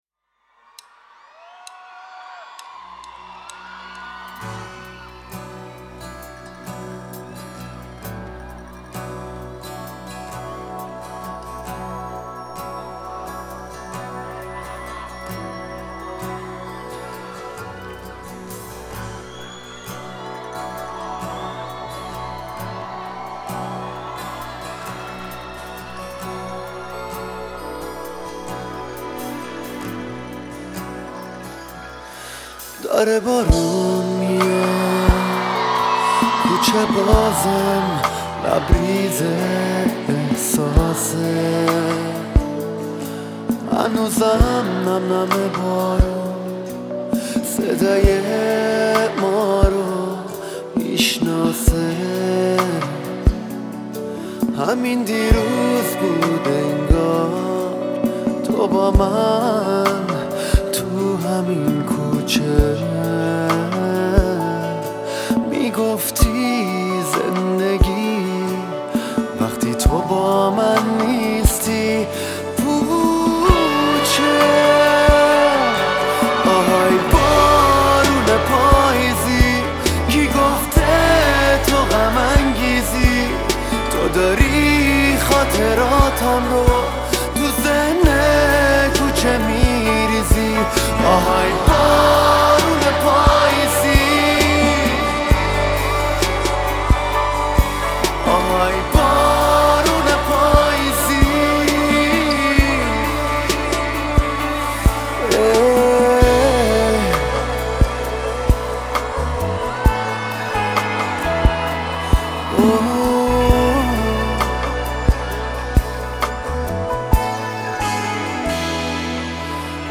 اجرا شده در کنسرت